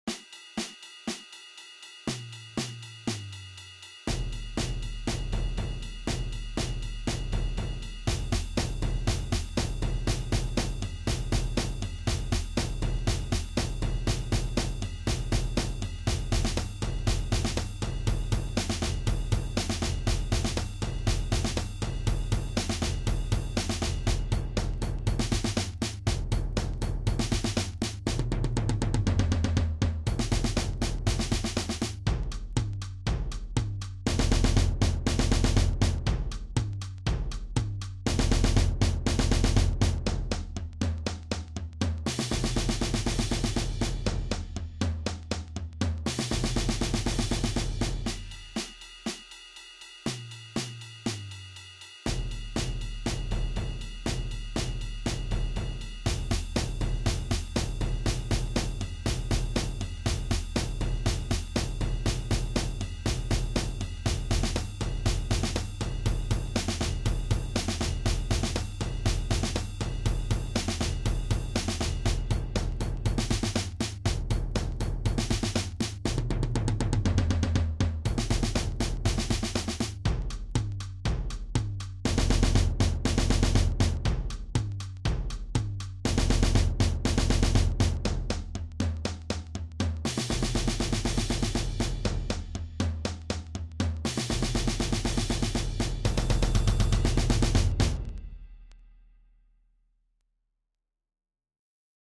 Jeugd Ensemble
Snare drum Tom toms Bass drum Drumset